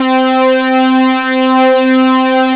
MINI LEAD 1.wav